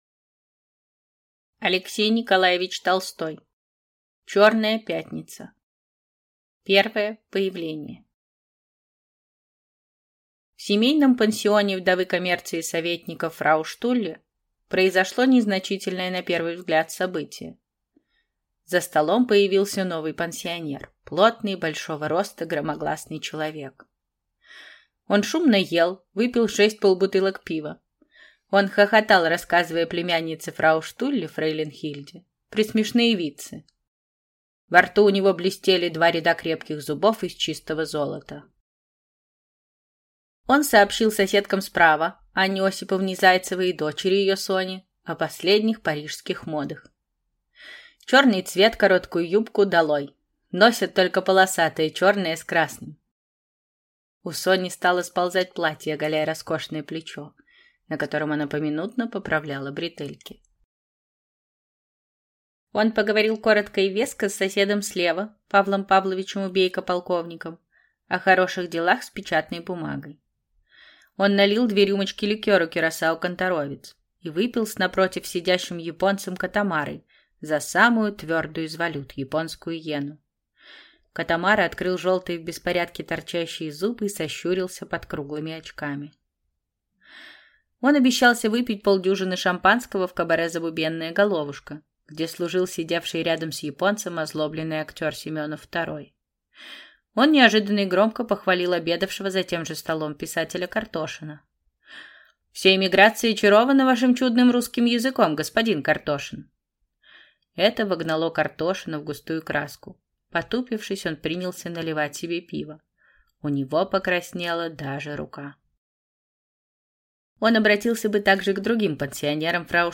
Аудиокнига Чёрная пятница | Библиотека аудиокниг